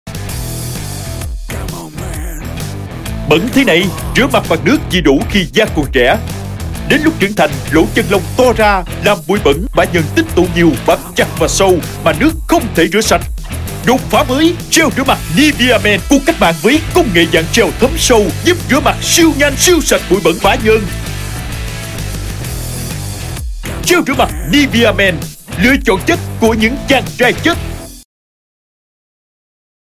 男越南1T-2 越南语男声 低沉|激情激昂|大气浑厚磁性|沉稳|娓娓道来|科技感|积极向上|时尚活力|神秘性感|素人